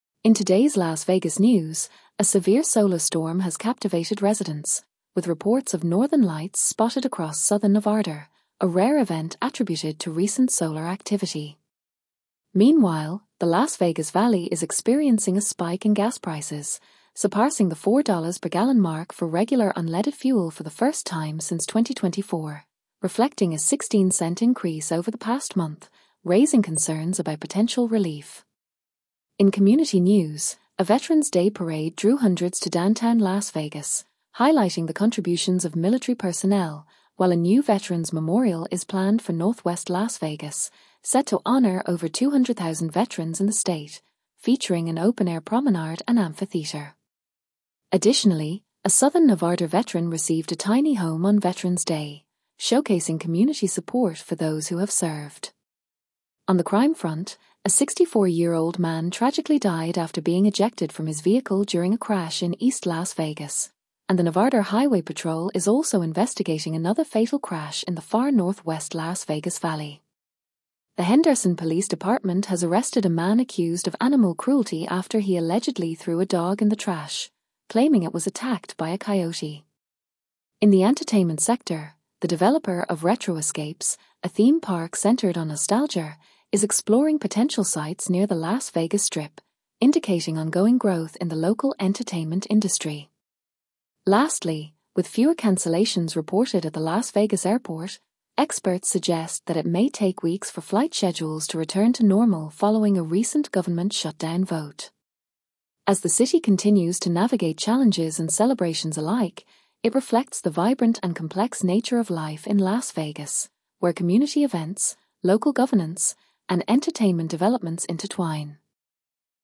Regional News